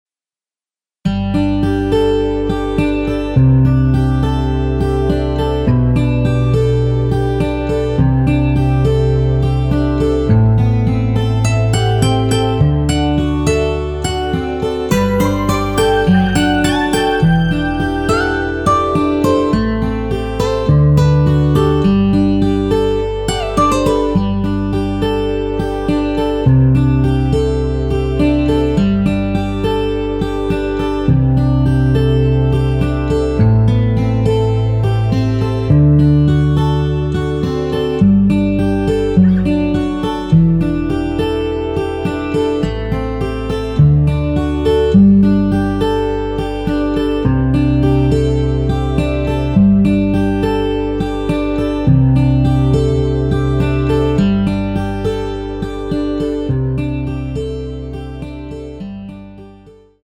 여성분이 부르실수 있는키로 제작 하였습니다.(미리듣기 참조)
앞부분30초, 뒷부분30초씩 편집해서 올려 드리고 있습니다.
중간에 음이 끈어지고 다시 나오는 이유는